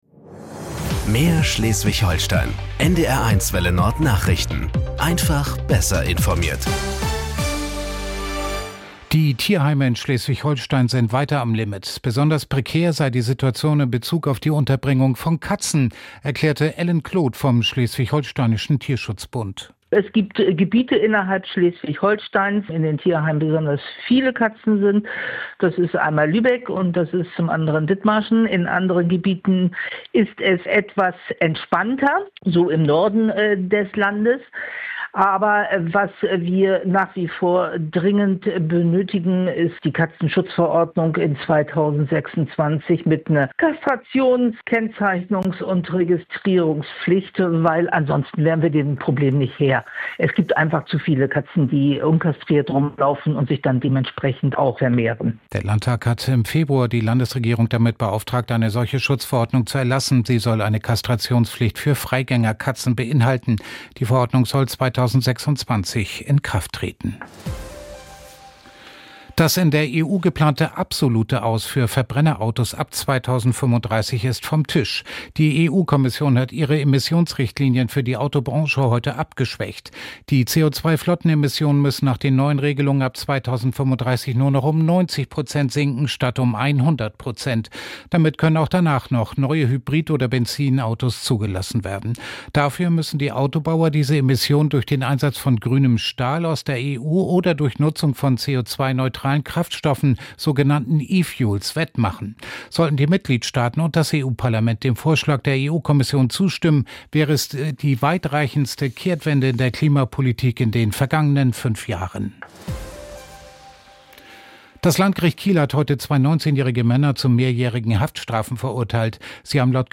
Nachrichten 18:00 Uhr - 16.12.2025 ~ NDR 1 Welle Nord – Nachrichten für Schleswig-Holstein Podcast